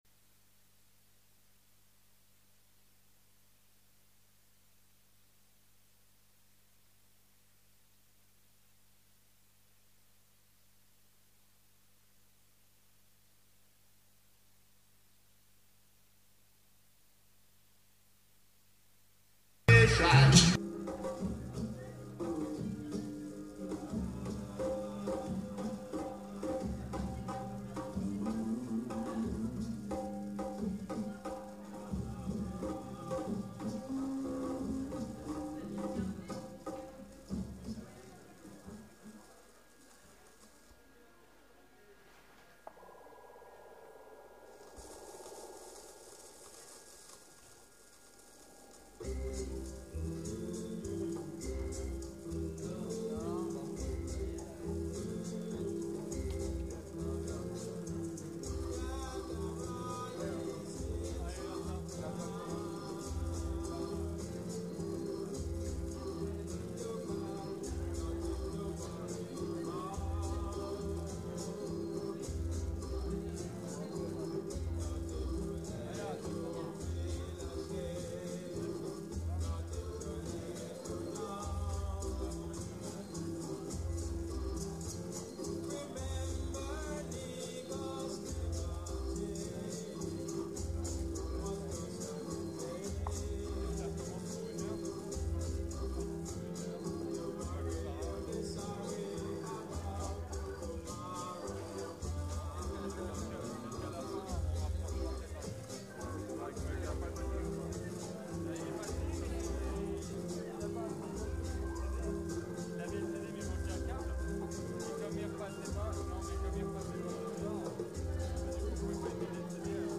Timkat Celebration
Jah Youth Family & Gangunguru Hi-Fi did very well playing on I&I set.